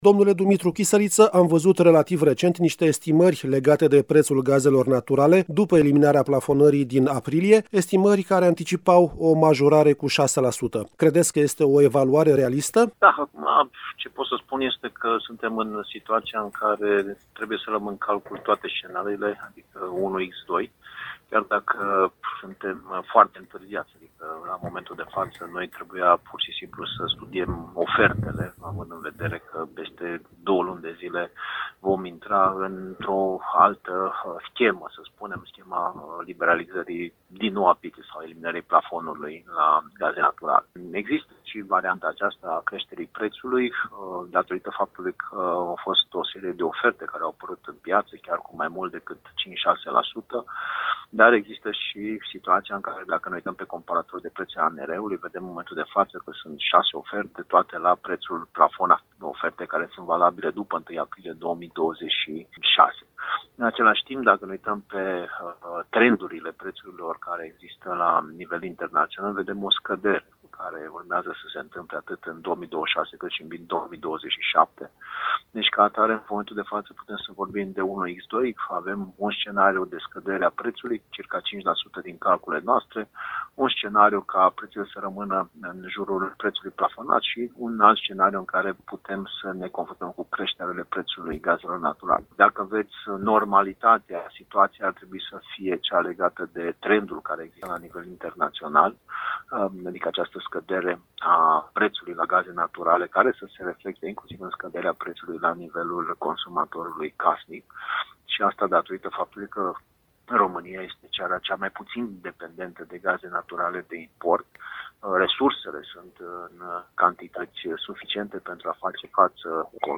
a discutat subiectul cu